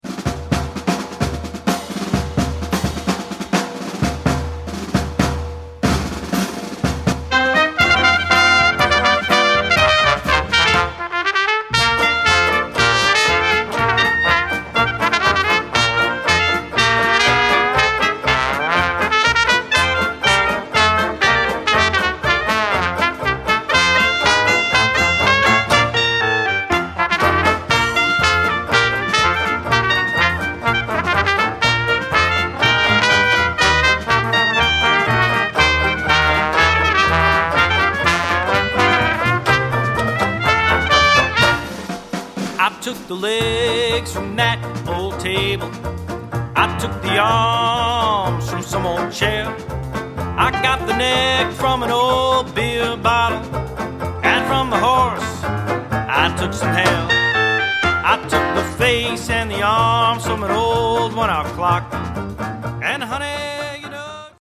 Piano
Clarinet
Trombone
Trumpet
Banjo
Percussion
Guitar & Vocal